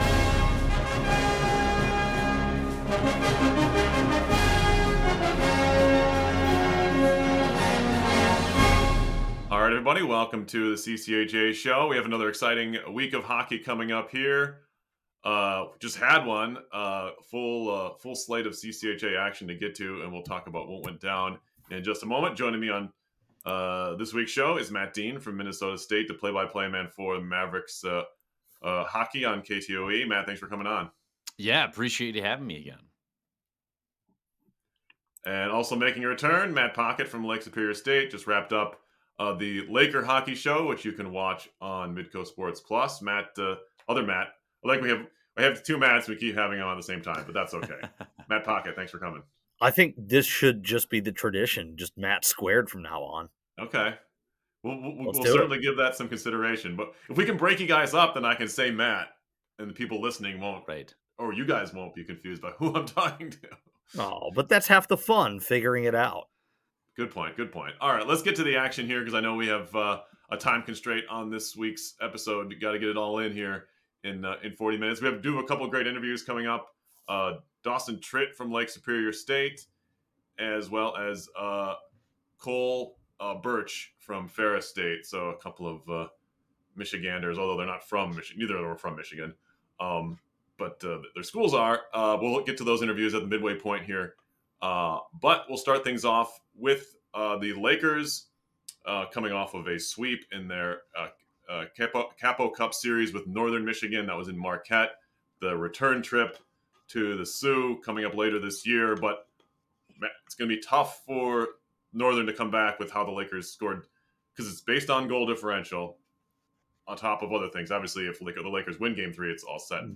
Featuring interviews